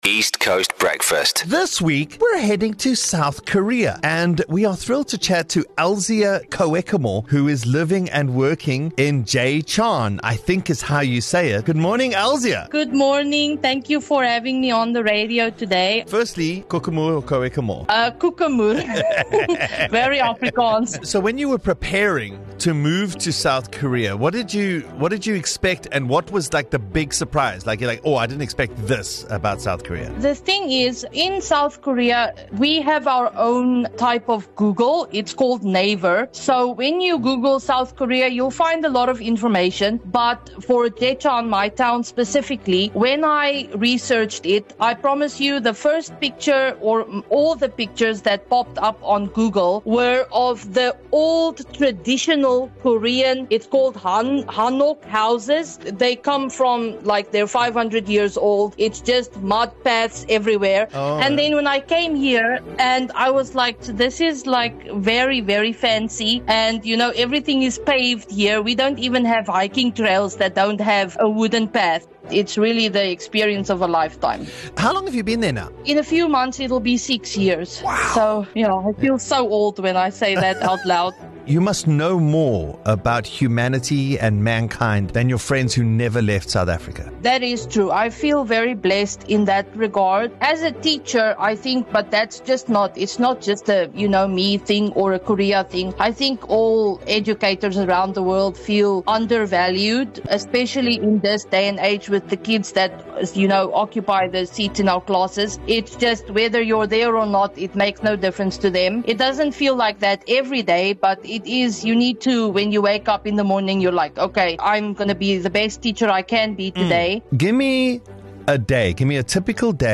The East Coast Radio Breakfast Show is a fun, and hyperlocal radio show that will captivate and entertain you.